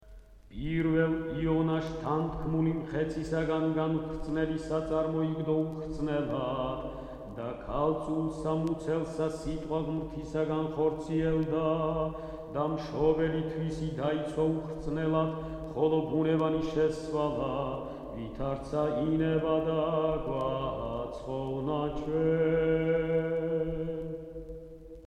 Christmas carols
Keywords: ქართული ხალხური სიმღერა